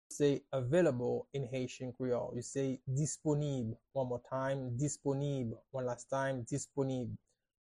“Available” in Haitian Creole – “Disponib” pronunciation by a native Haitian tutor
“Disponib” Pronunciation in Haitian Creole by a native Haitian can be heard in the audio here or in the video below:
How-to-say-Available-in-Haitian-Creole-–-Disponib-pronunciation-by-a-native-Haitian-tutor.mp3